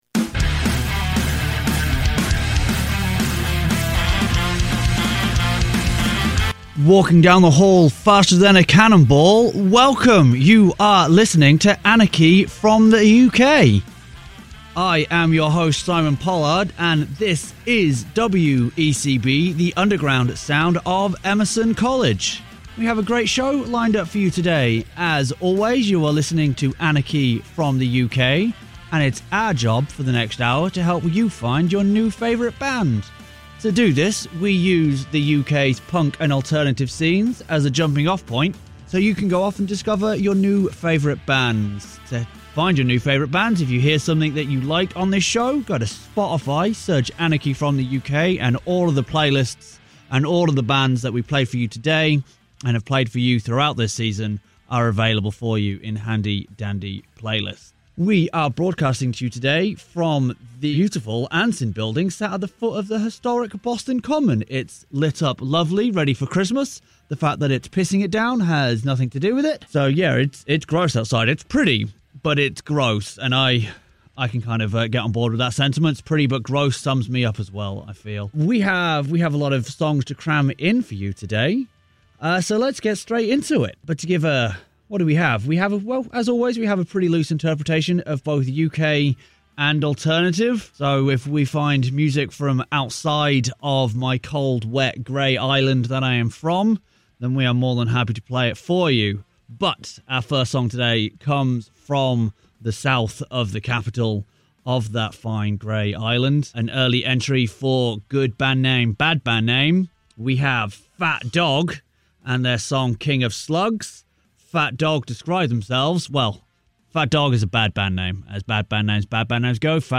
Broadcasting from the historic Boston Common at Emerson College's WECB, this episode is packed with an eclectic mix of tracks that promise to introduce you to your next favourite band.